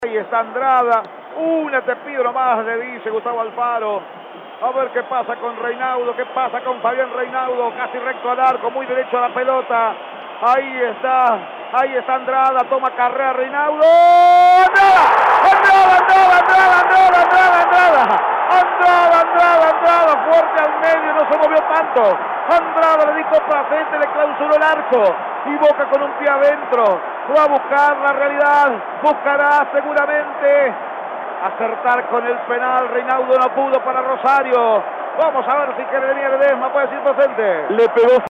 Con transmisión de Radio Eme, Boca se consagró campeón de la SuperCopa Argentina en el estadio Malvinas Argentina de Mendoza.